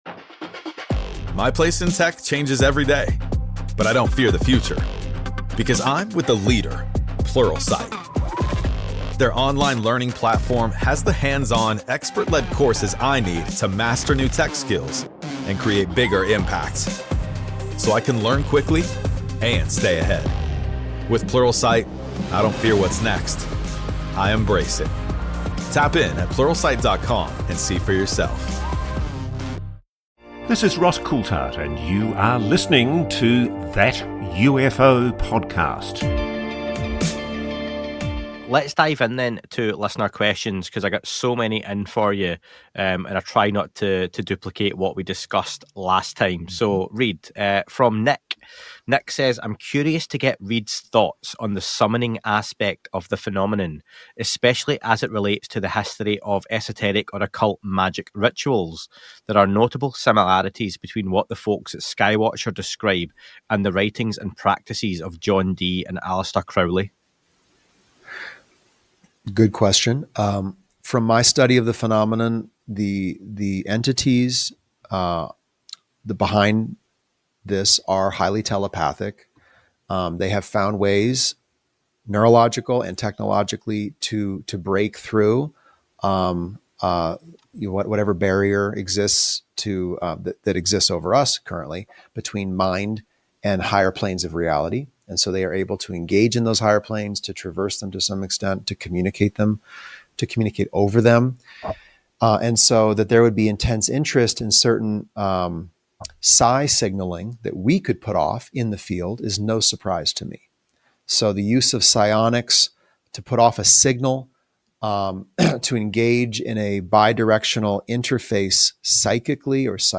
an in-depth discussion on the strategic and existential implications of the UFO phenomenon. They examine the Age of Disclosure documentary, the evolving role of non-human intelligence, historical and modern UAP engagements, South American incidents, and the challenges surrounding governmental transparency. The episode also explores citizen-driven detection efforts, political disclosure possibilities, and the influence of NHI on belief systems, technology, and global stability.